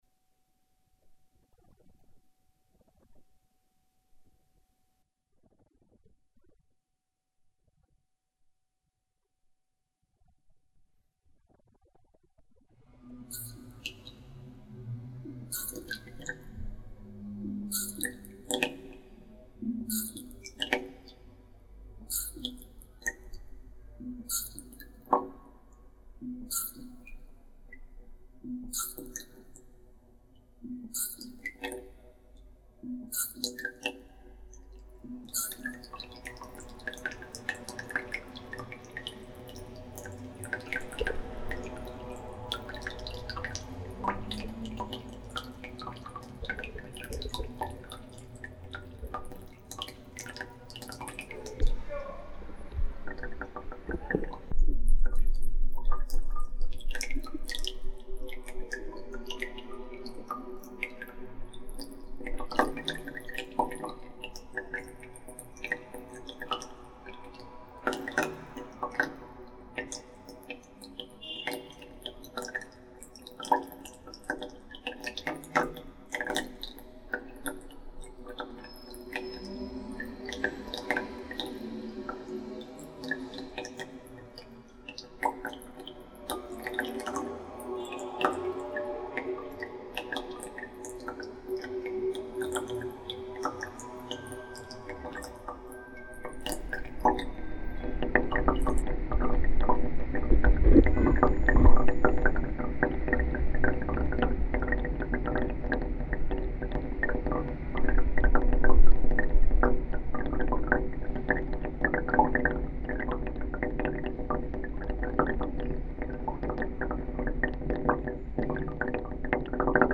Buenos Aires chair and watter sounds
Category: Travel/Int'l   Right: Personal